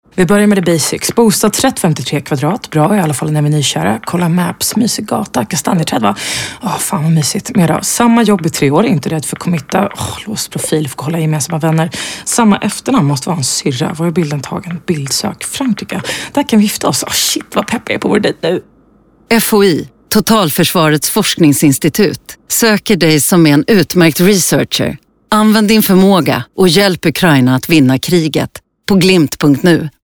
Radioreklam till tjejer, mp3